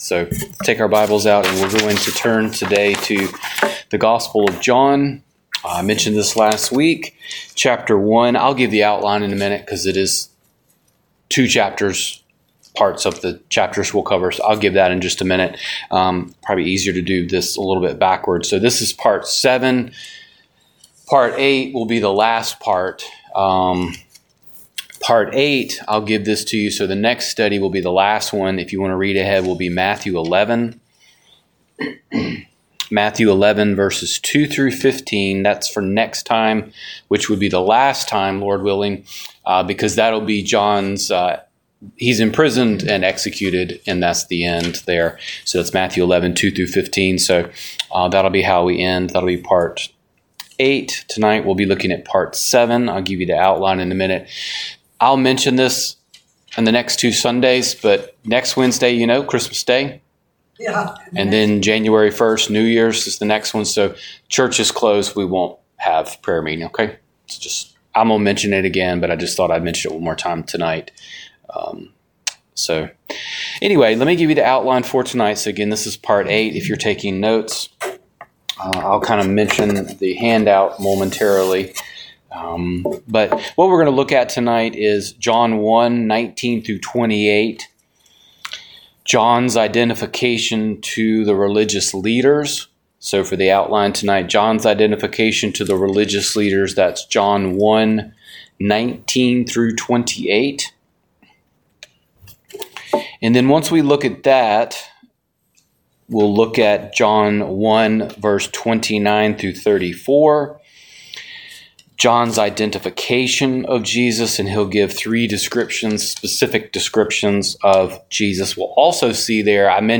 Download Download The Life of John the Baptist - Part 7 Wed. Night Bible Study Matthew 23:37-39 "The King's Lament" Wed.